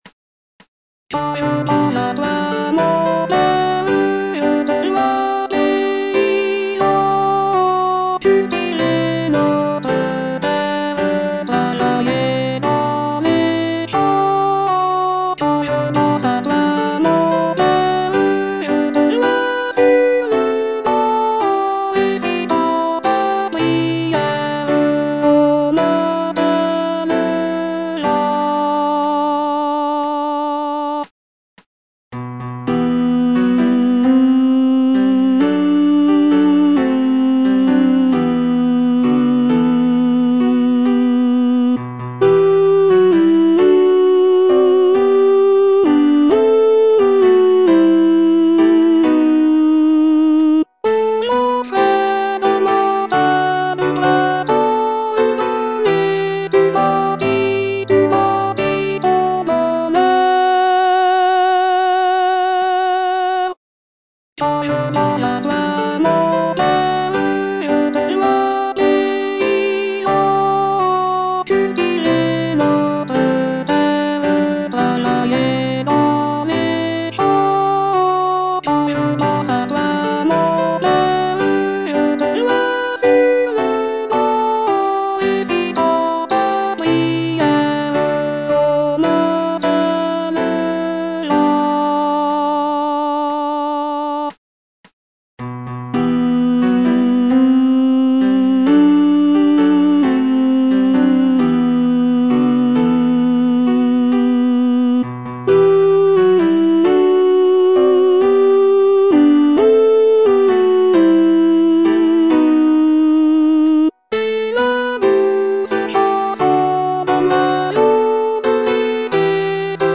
Alto II (Voice with metronome)